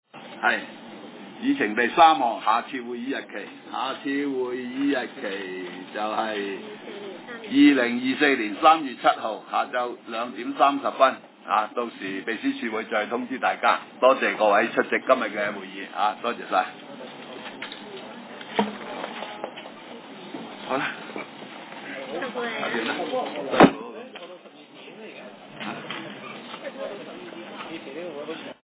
大埔区议会 - 委员会会议的录音记录
会议的录音记录